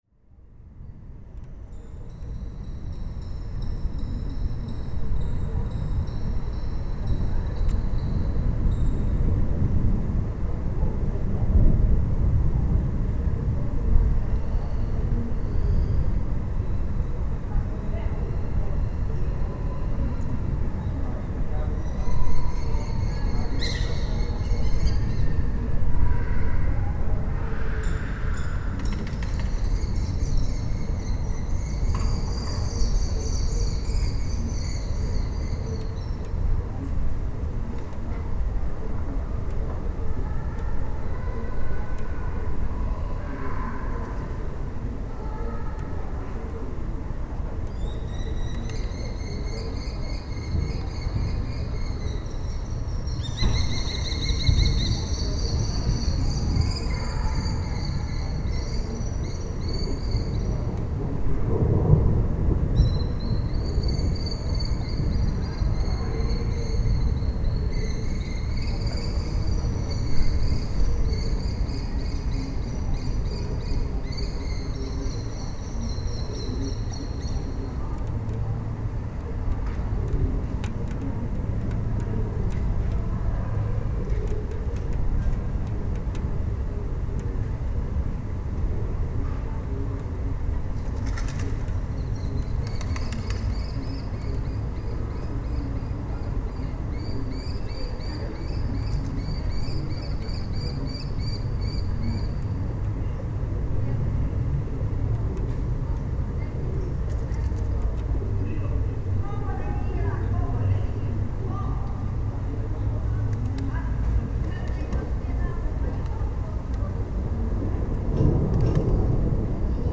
it starts to rain | Zvuky Prahy / Sounds of Prague
začiná pršet
začiná pršet Tagy: exteriér Autor